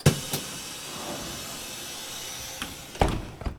transport
Bus Door Closing 1